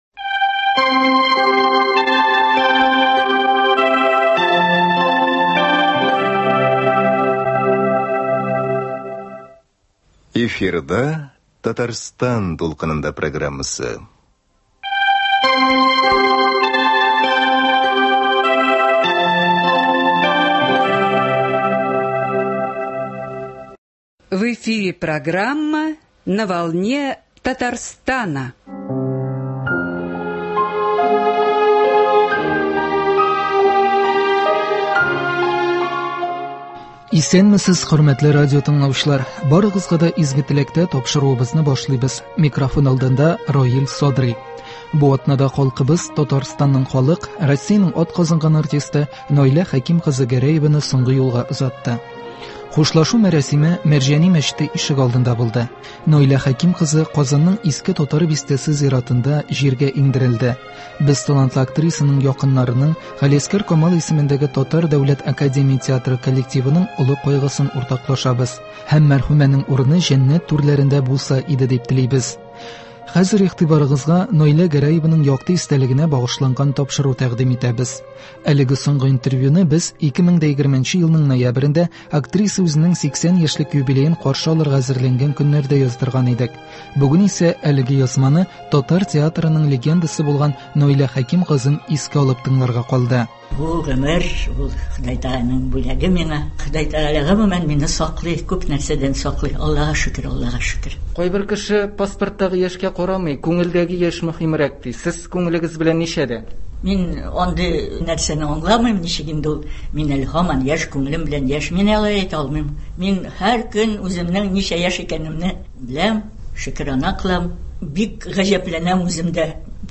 Бүген игътибарыгызга Наилә Гәрәеваның якты истәлегенә багышланган тапшыру тәкъдим итәбез. Әлеге соңгы интервьюны без 2020 елның ноябрендә, актриса үзенең 80 яшьлек юбилеен каршы алырга әзерләнгән көннәрдә яздырган идек.